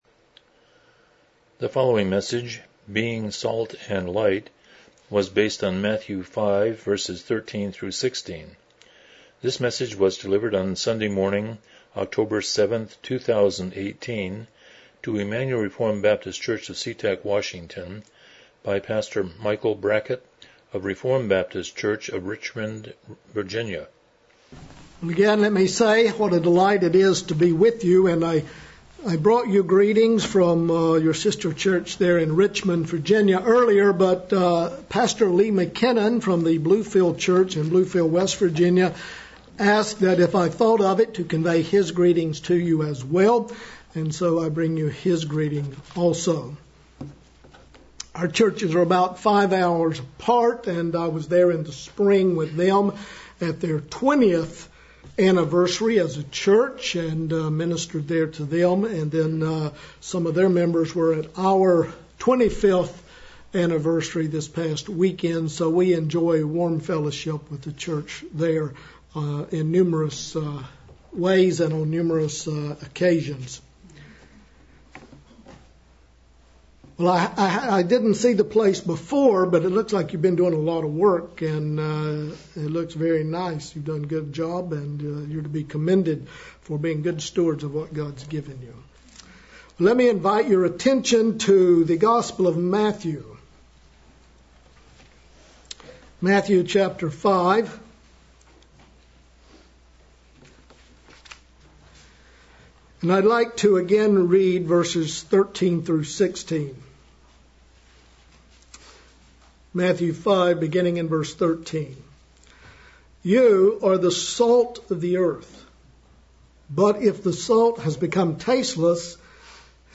Matthew 5:13-16 Service Type: Morning Worship « Prayer in Spiritual Warfare Songs of Ascent